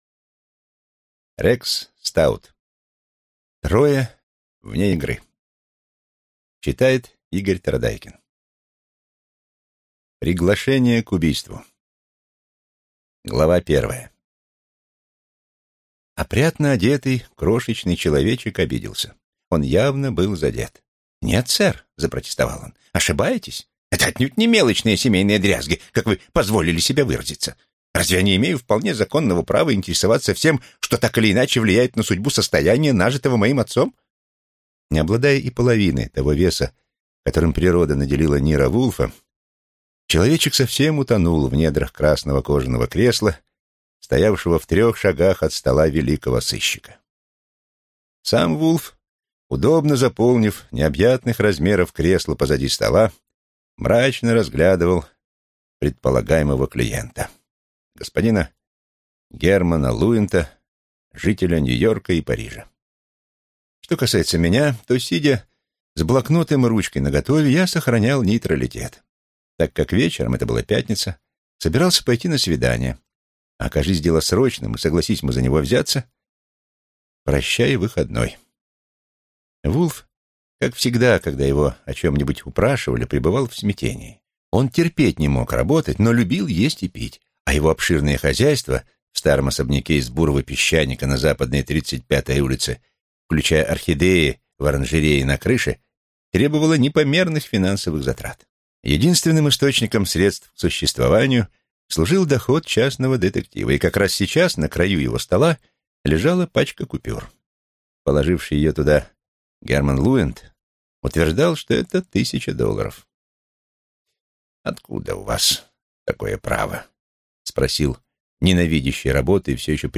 Аудиокнига Трое вне игры | Библиотека аудиокниг
Прослушать и бесплатно скачать фрагмент аудиокниги